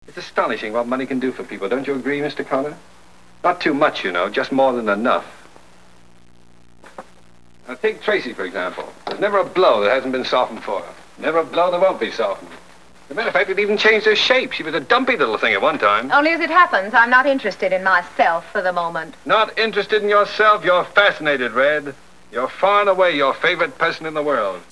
My favorite funny moviescenes on wav file!